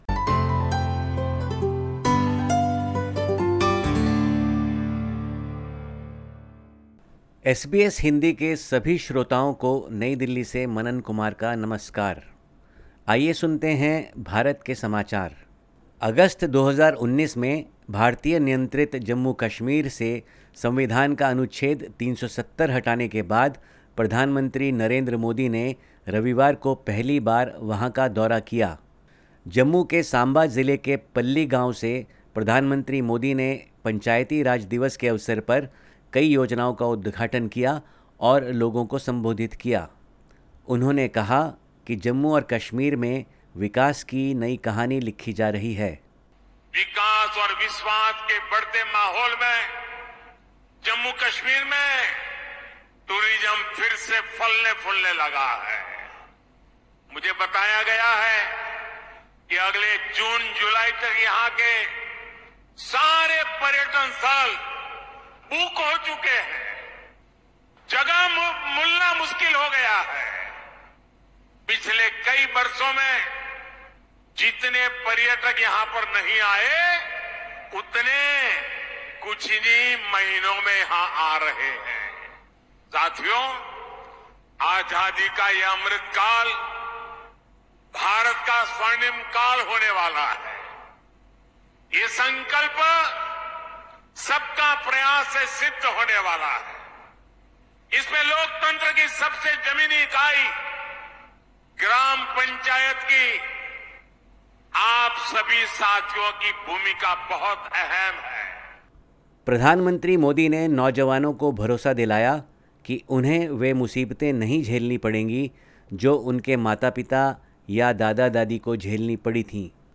Listen to the latest SBS Hindi report from India. 25/04/2022